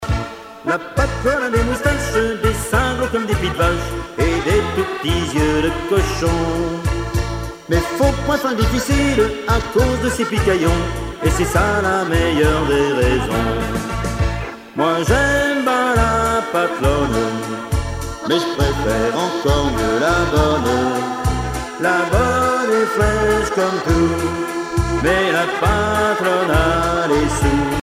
danse : valse
Genre strophique
Pièce musicale éditée